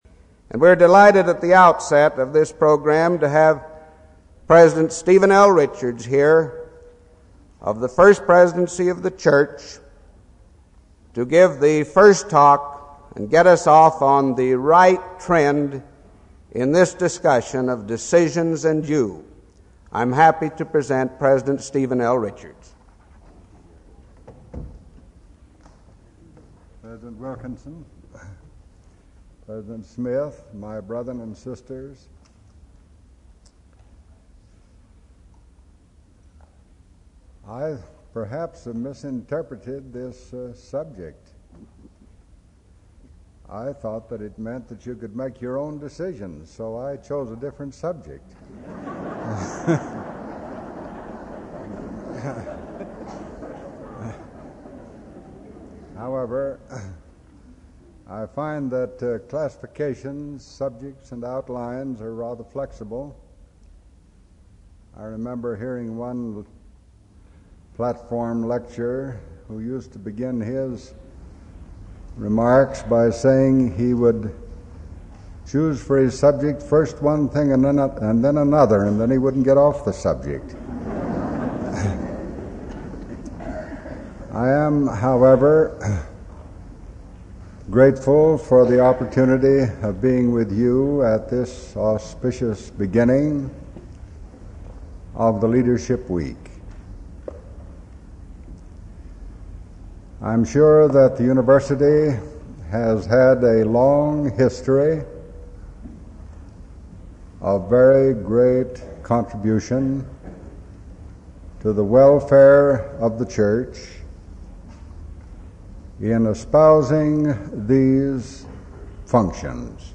Leadership Week Devotional